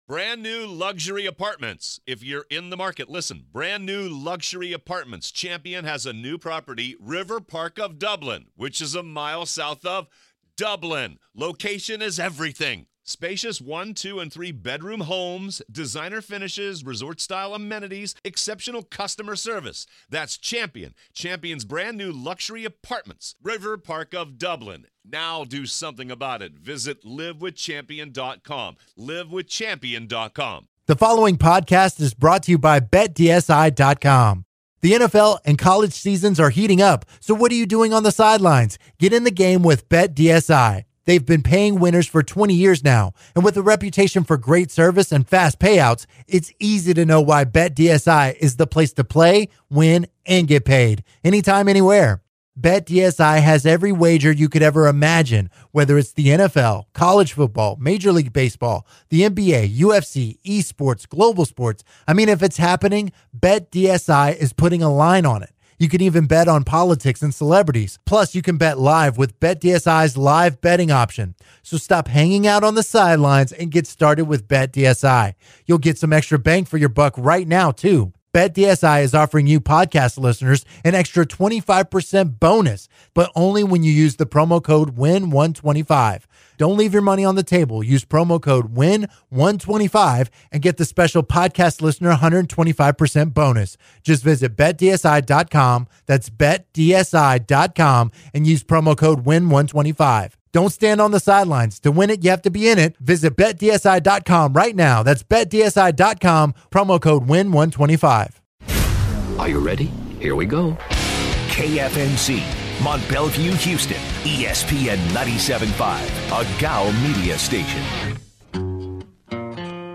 joins the show via phone to discuss the Astros’ loss and looking forward to next’s season roster.